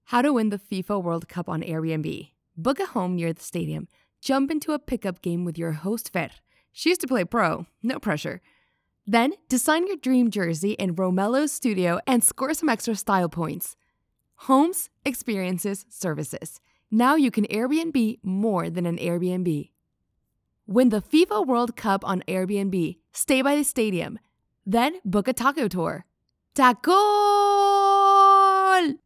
Home Studio, Rode NT1 Kondensatormikrofon der 5. Generation